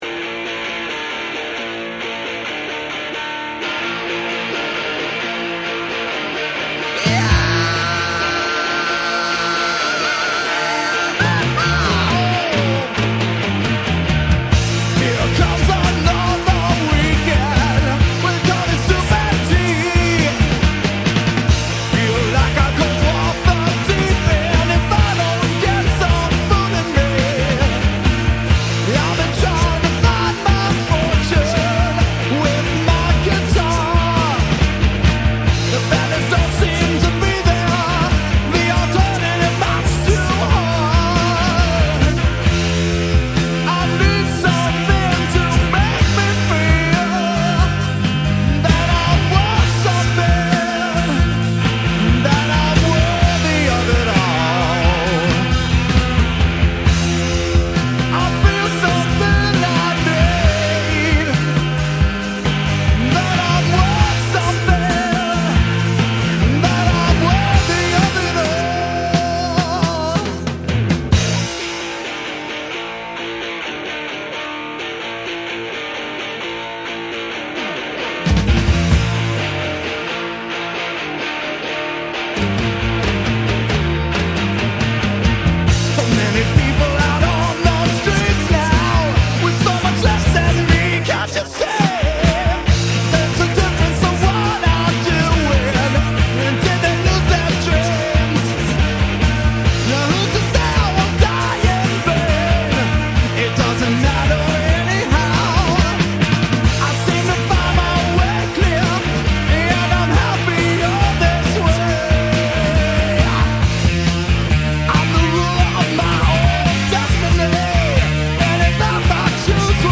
Vocals
Drums
Guitar
Bass